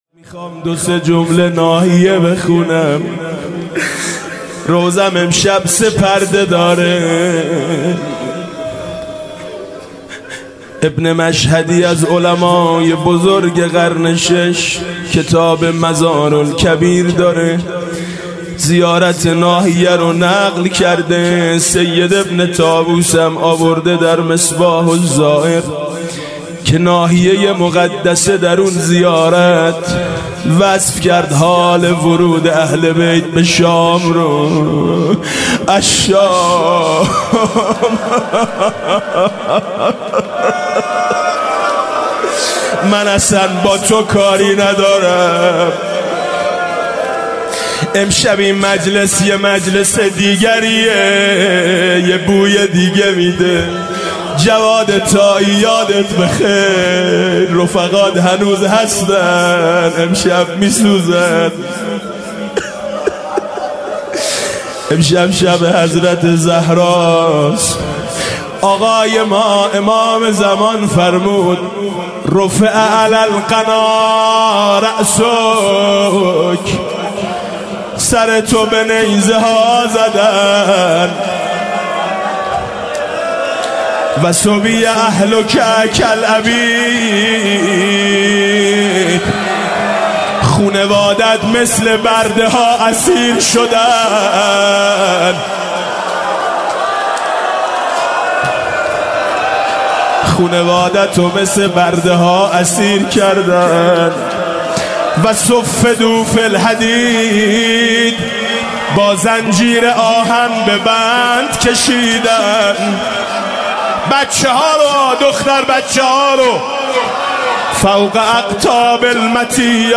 خیمه گاه - شب سوم محرم - به نام نامیِ حضرت رقیه(س) - محرم 95 | روضه 2 | ای شام